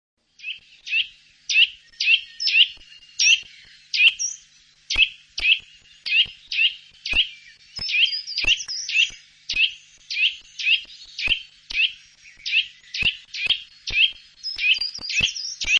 Der Gesang setzt sich aus mehreren lauten Strophen unterschiedlichen Typs zusammen. Meist sind es langsame Folgen gleicher Pfeiftöne, die etwas an- oder absteigen können, etwa wie ,,wuih wuih wuih wuih..." oder ,,wiiü wiiü wiiü wiiü". zurück zur Übersicht >
kleiber.mp3